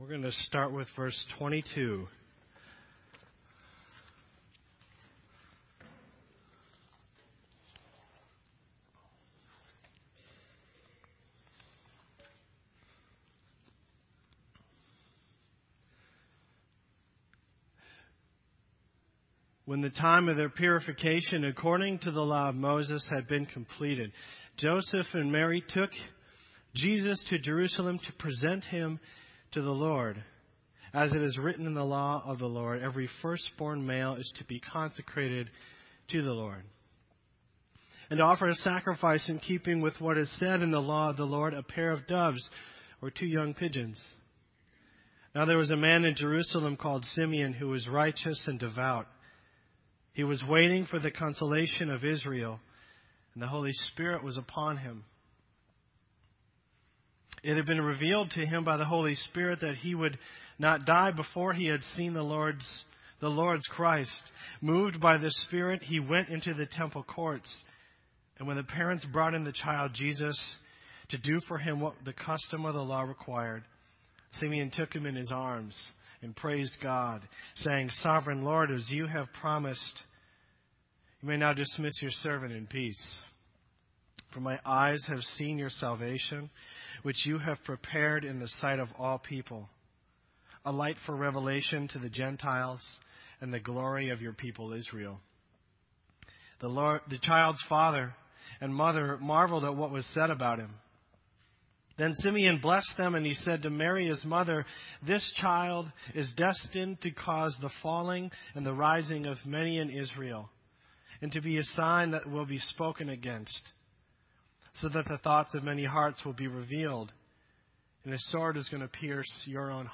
This entry was posted in Sermon Audio on December 29